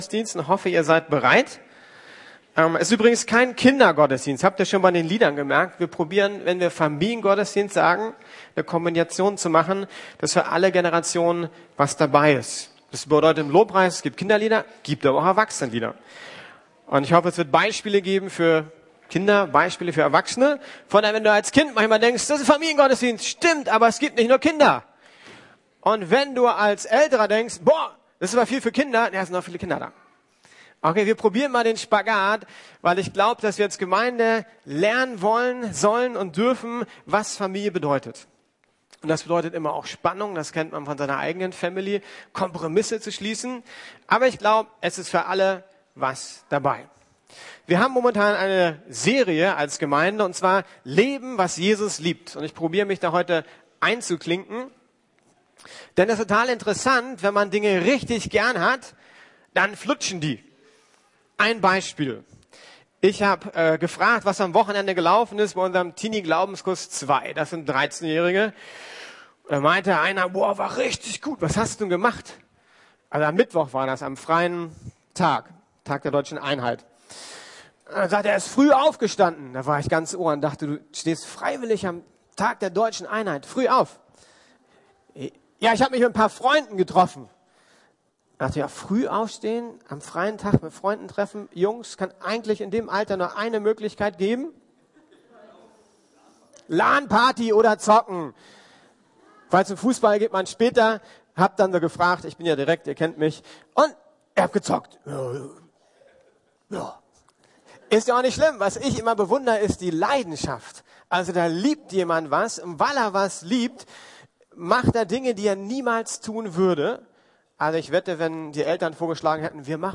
Lieben was Jesus liebt: Vertrauen ~ Predigten der LUKAS GEMEINDE Podcast